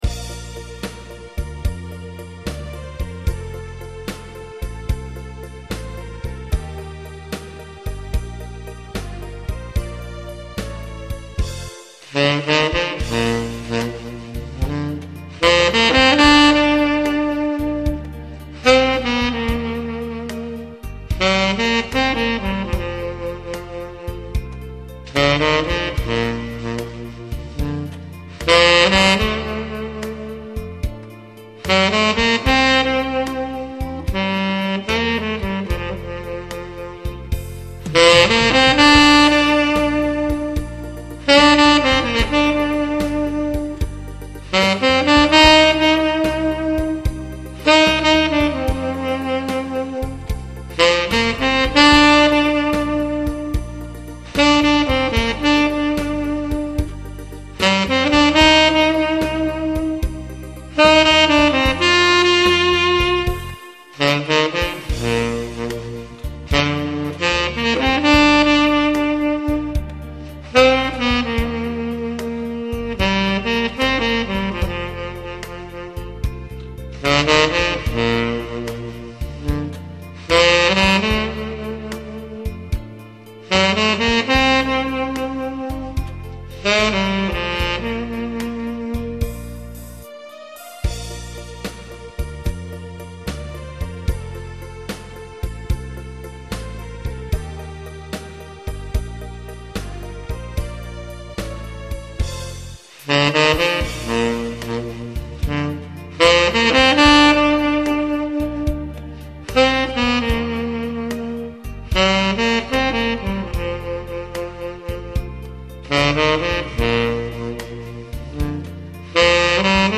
해놓고 보니 완전 엉터리입니다.